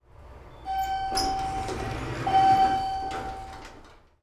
Elevator Ding
ding elevator ping sound effect free sound royalty free Sound Effects